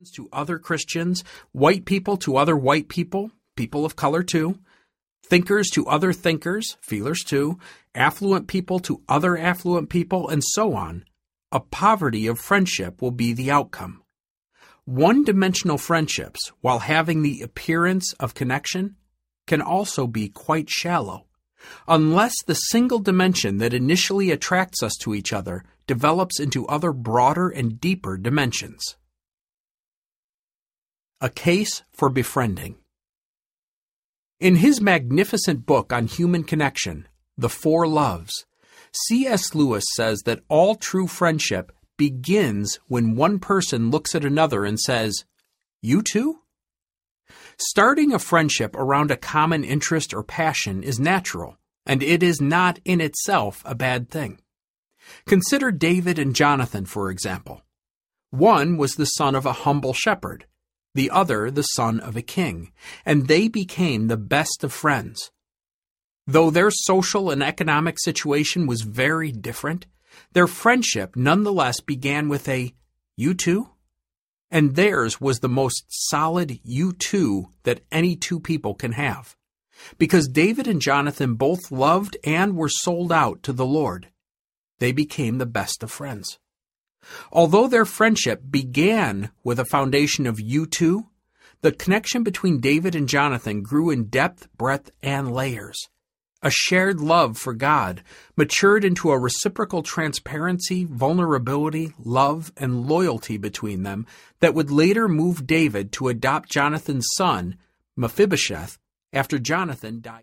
Befriend Audiobook
Narrator
6.37 Hrs. – Unabridged